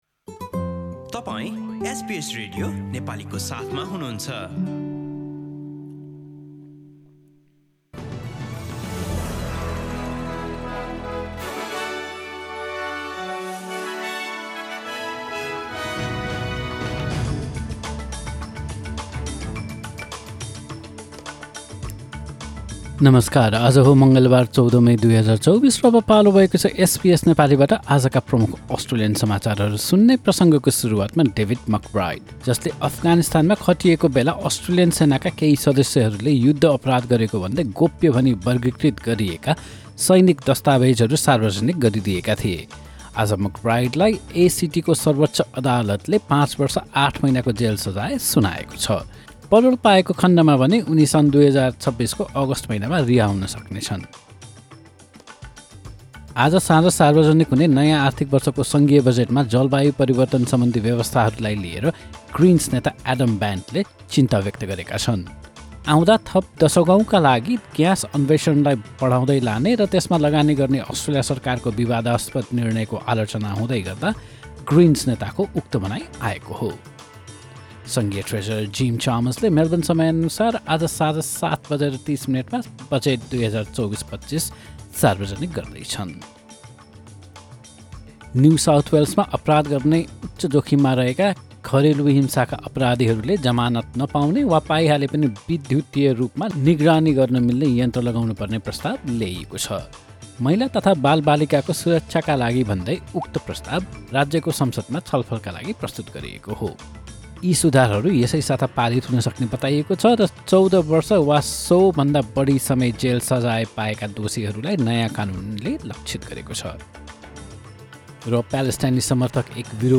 Listen to the latest bitesize top news from Australia in Nepali.